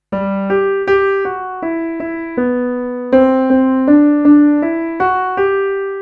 Ex-2a-melody.mp3